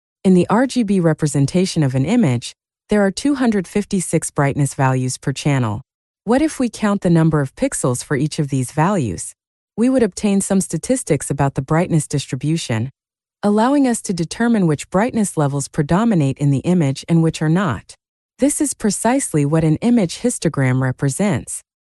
Так выглядит и звучит онлайн читалка в Edge с голосом Emma en-US online.